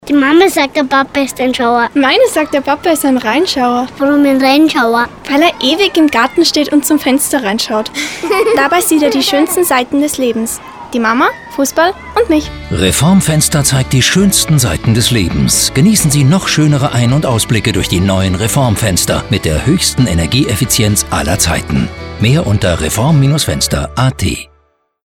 Reform_Radio_Spot1.mp3